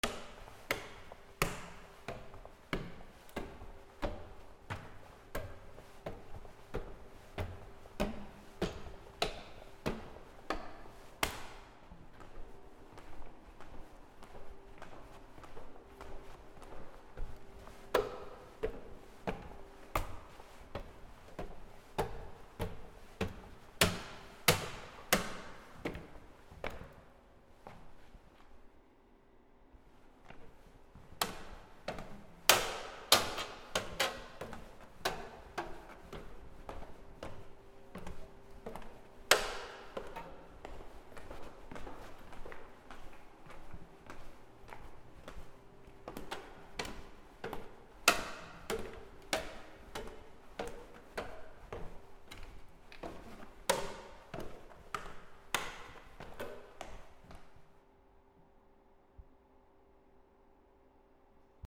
/ I｜フォーリー(足音) / I-190 ｜足音 階段
階段の上り下り 金属の滑り止めあり マンション廊下